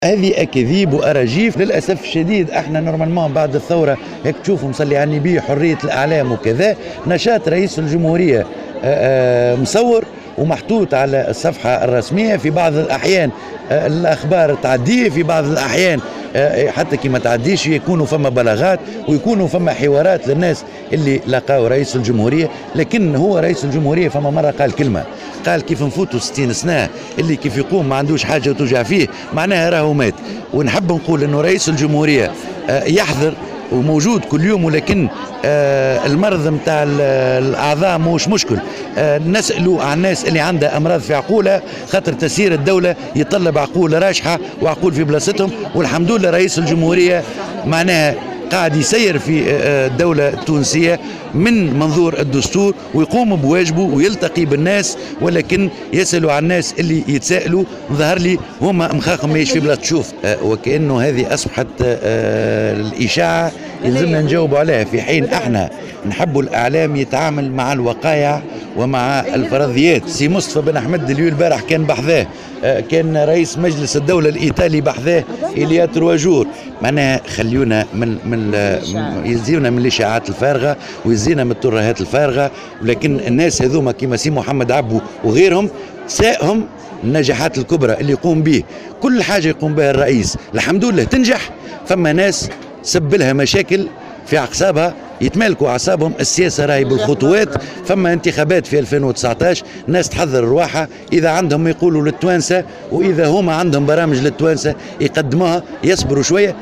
Dans une déclaration accordée à la correspondante de Jawhara FM, en marge du 14ème congrès de l'Union nationale de la femme tunisienne (UNFT) a assuré que le chef de l'Etat exerce ses fonctions normalement.